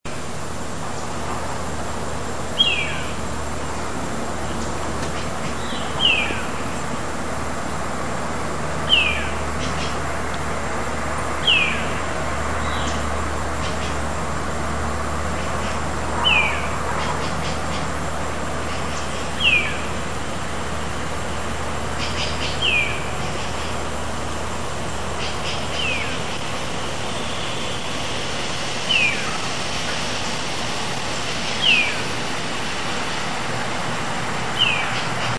Gavilán Patas Largas Aguilucho Pampa
Crane Hawk Black- collared Hawk